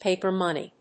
アクセントpáper móney